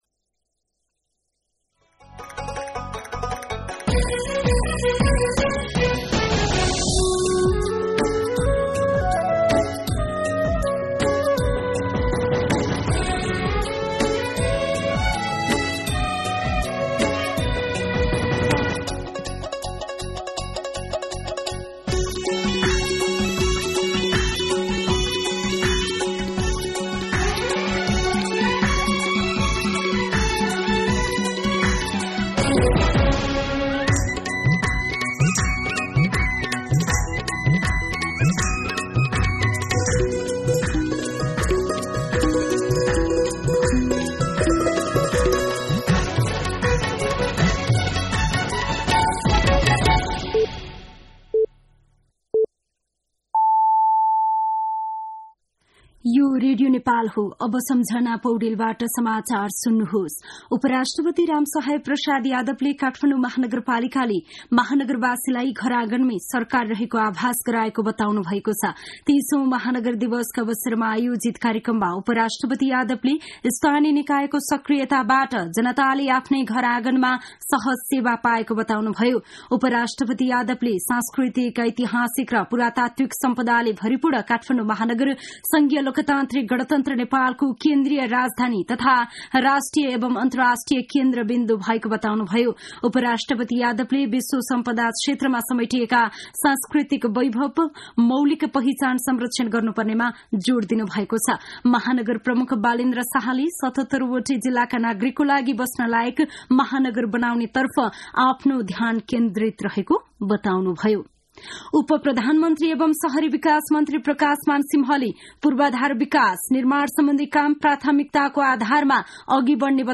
दिउँसो ४ बजेको नेपाली समाचार : ३० मंसिर , २०८१
4-pm-Nepali-News-1.mp3